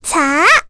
Requina-Vox_Casting3.wav